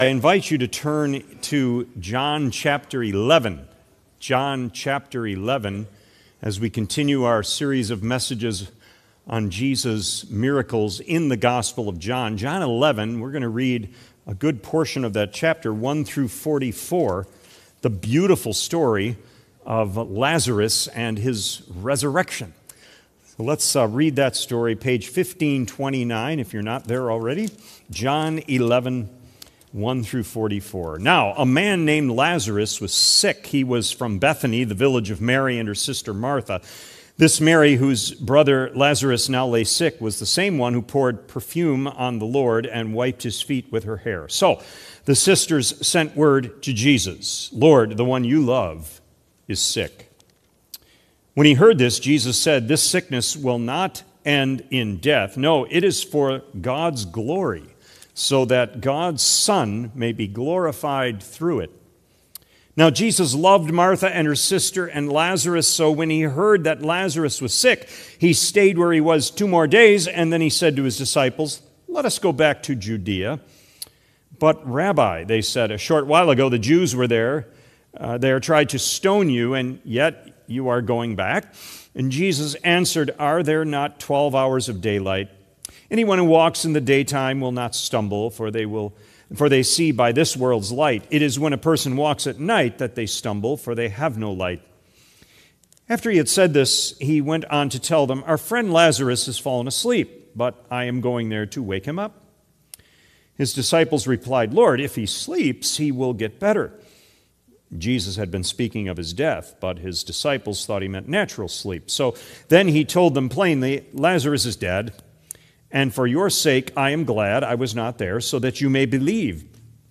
Sermon Recordings | Faith Community Christian Reformed Church
“Come on out, Harvey!” March 15 2026 P.M. Service